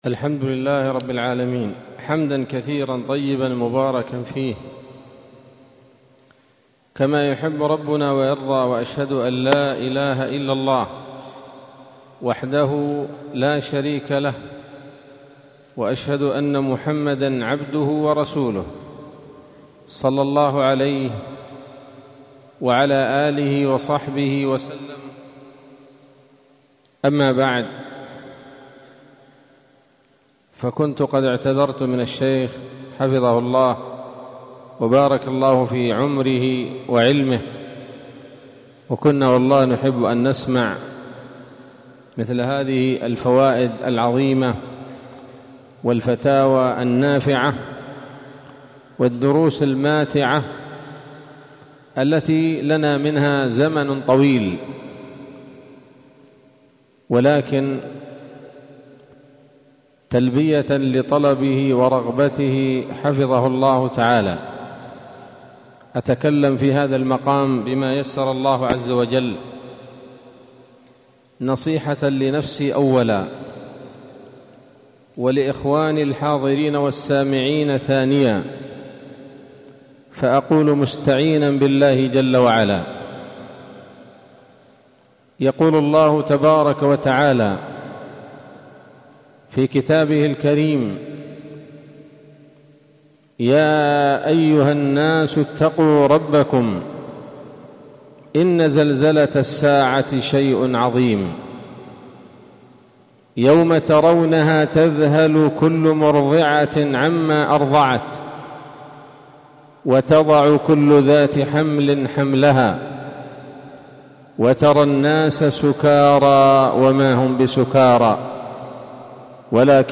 كلمة قيمة بعنوان: (( من مواقف وأهوال يوم القيامة )) ظهر الثلاثاء 14 شهر رجب 1446هـ، بمسجد إبراهيم بشحوح - سيئون